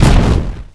rose_rocket_explo_01.wav